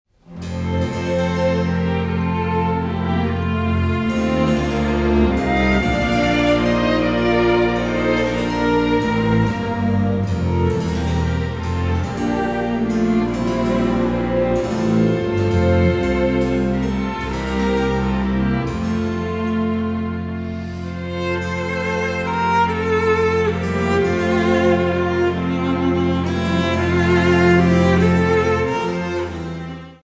soprano
• Studio : Église Saint-Matthias